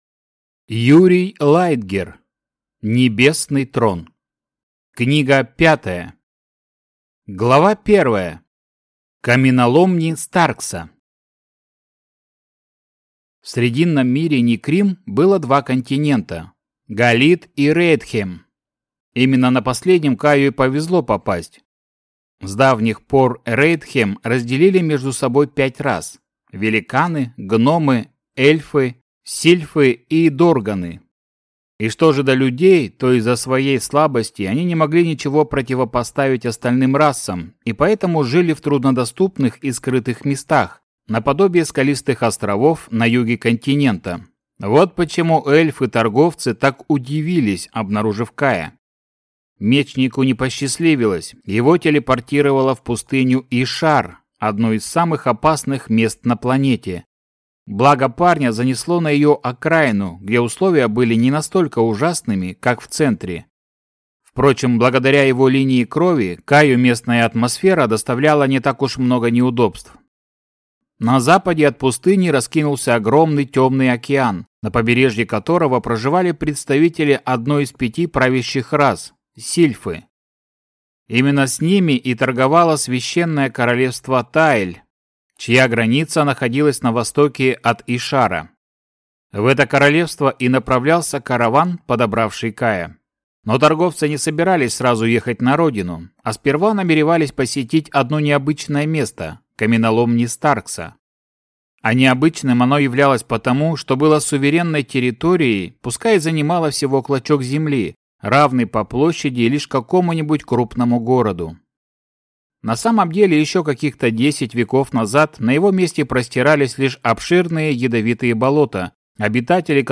Аудиокнига Небесный Трон. Книга 5 | Библиотека аудиокниг